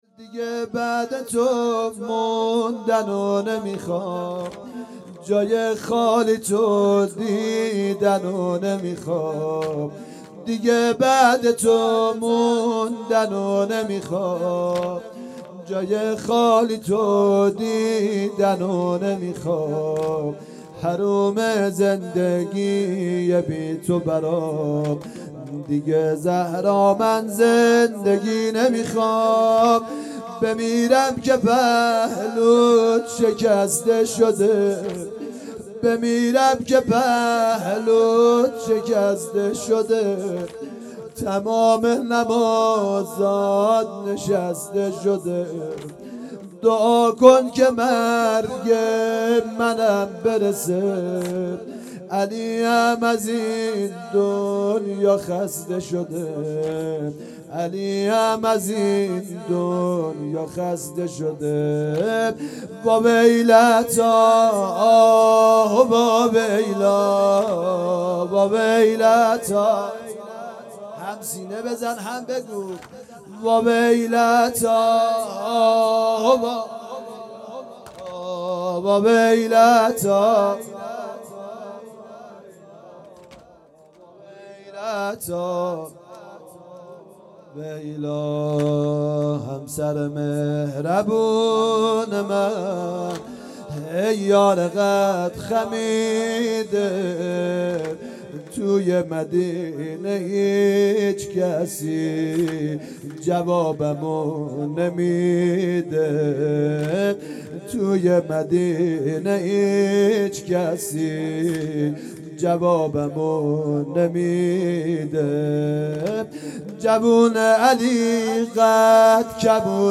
شهادت حضرت فاطمه زهرا سلام الله علیها - شب سوم - فاطمیه دوم